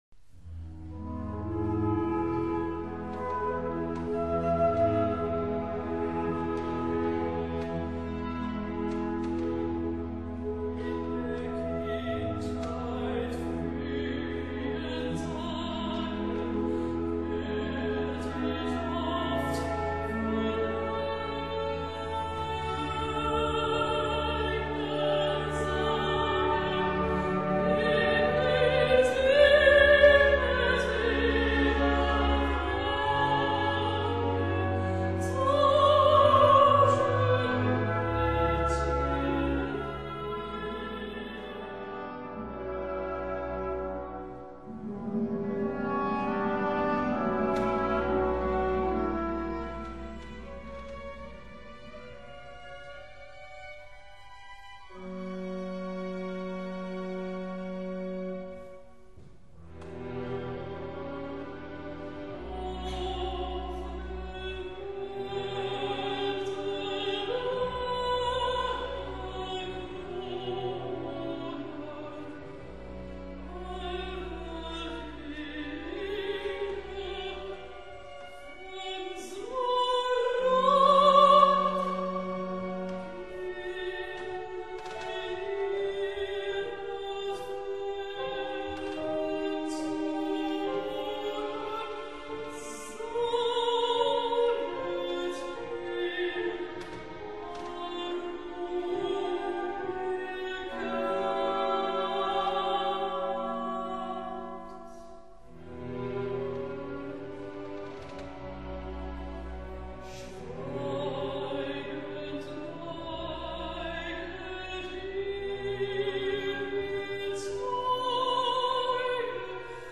Fra koncert i Skælskør Kirke, maj 2005